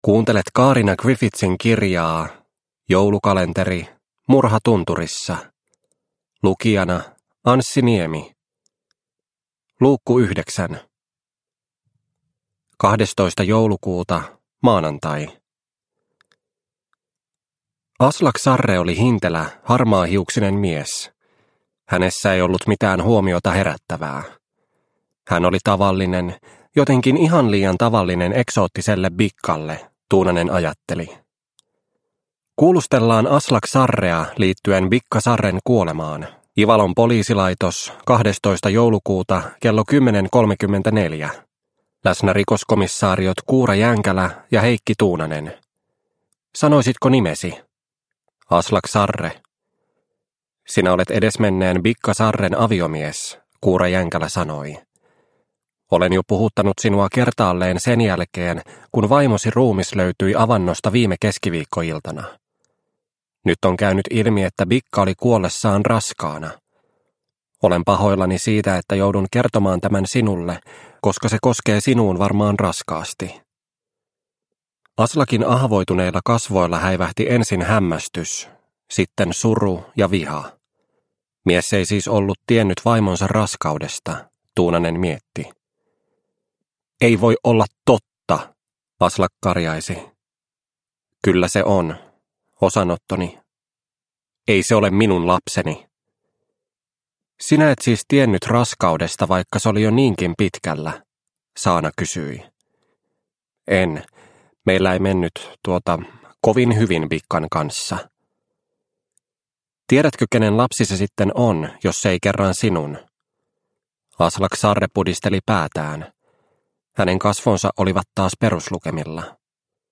Murha tunturissa - Osa 9 – Ljudbok – Laddas ner